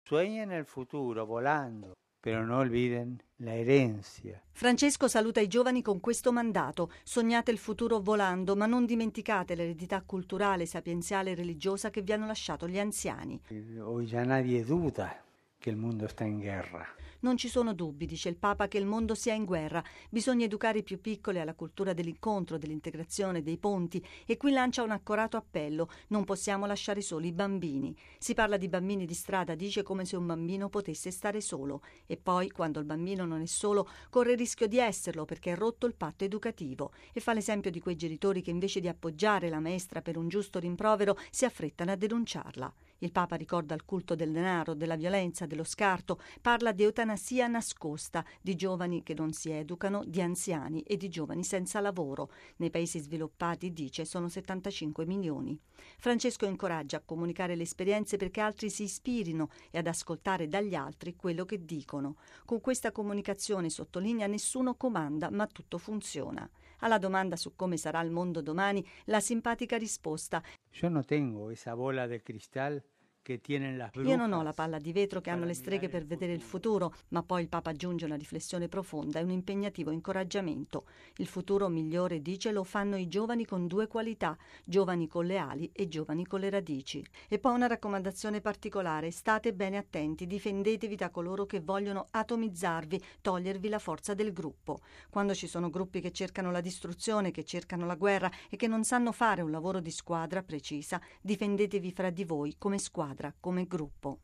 Il Pontefice ha dialogato in videoconferenza via internet con studenti di Paesi nei 5 continenti: Salvador, Sudafrica, Turchia europea (Istanbul), Israele, Australia, Camerun. Intense le risposte del Papa in un clima gioioso e familiare.